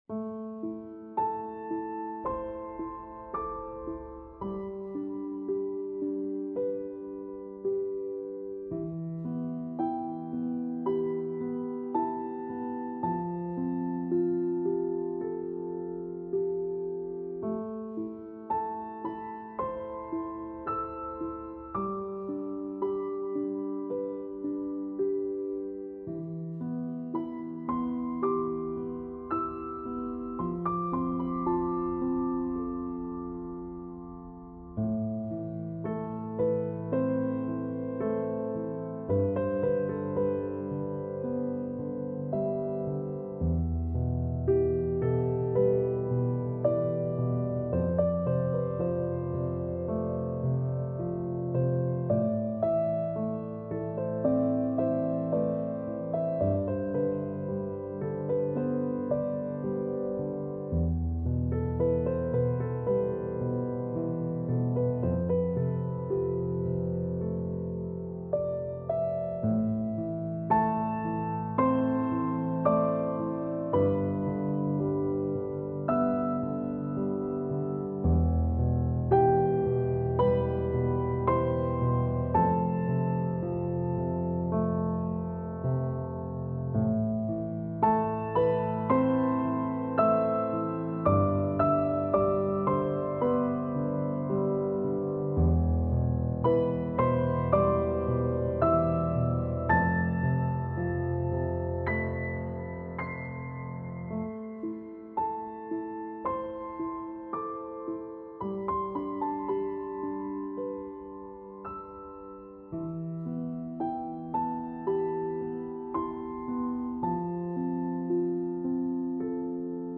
آرامش بخش ابری و بارانی پیانو غم‌انگیز موسیقی بی کلام
پیانو آرامبخش
موسیقی بی کلام غمگین